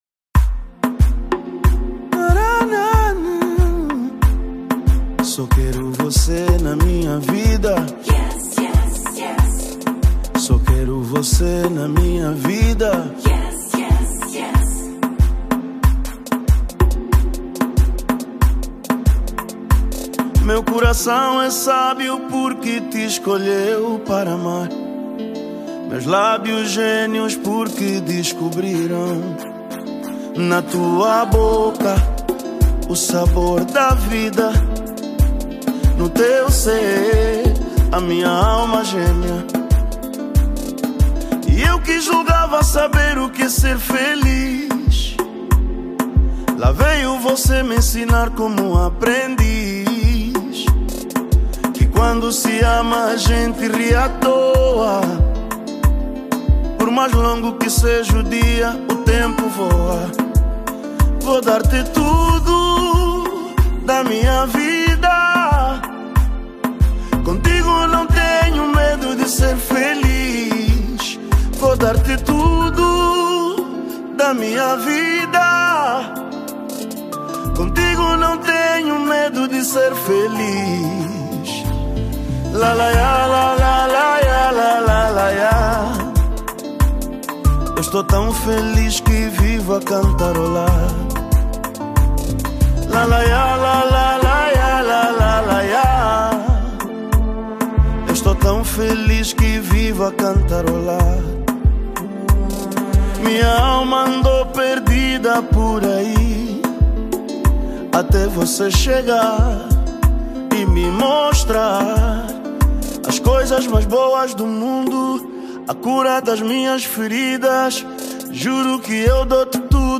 Kizomba